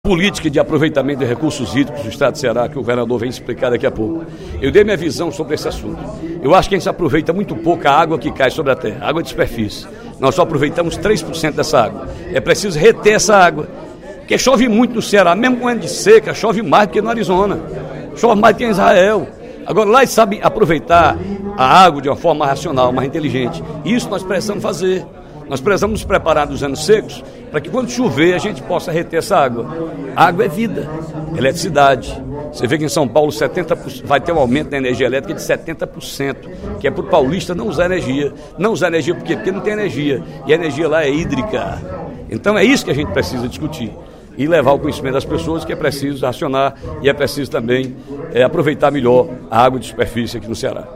Em pronunciamento durante o primeiro expediente da sessão plenária desta quarta-feira (25/02), o deputado Ferreira Aragão (PDT) elogiou a forma como o Ceará tem lidado com os problemas hídricos.